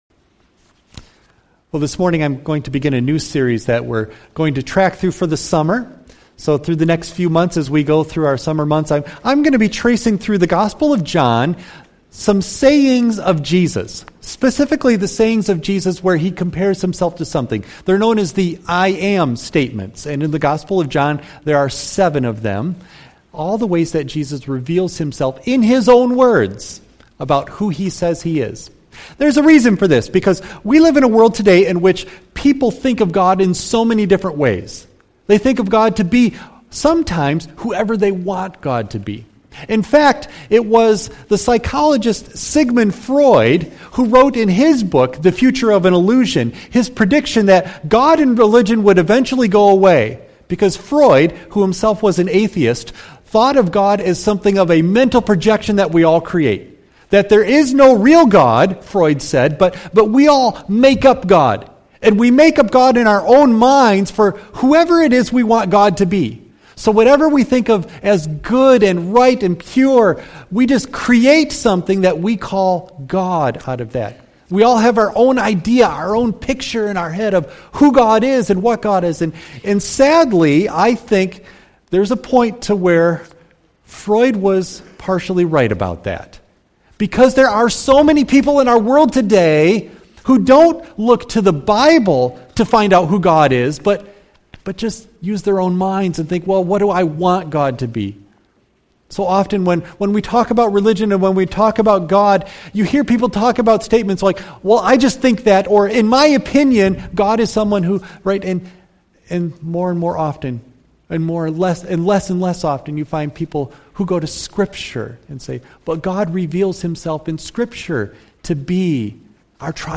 John 6:25-40 Service Type: Sunday AM Bible Text